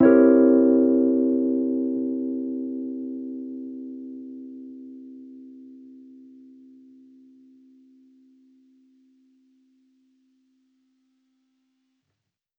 Index of /musicradar/jazz-keys-samples/Chord Hits/Electric Piano 1
JK_ElPiano1_Chord-C7b9.wav